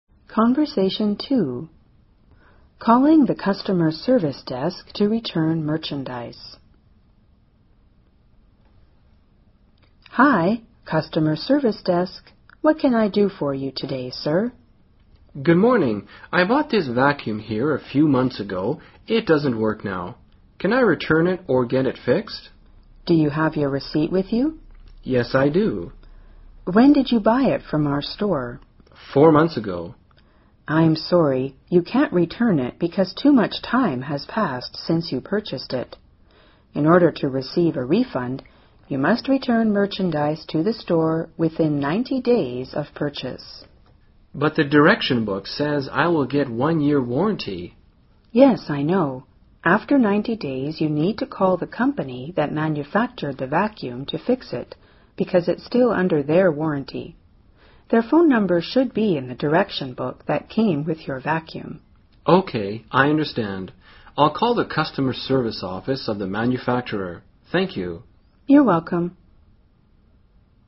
【对话2：打电话到客户服务柜台退货】